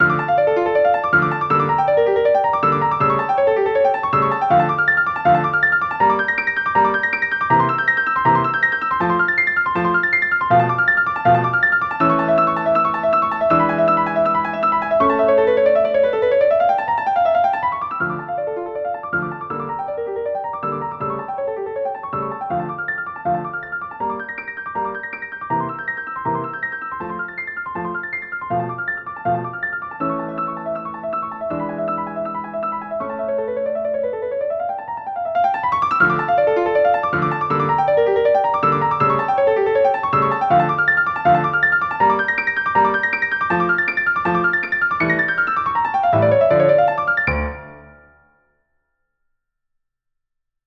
SYNTHESISED RECORDINGS LIBRARY
Piano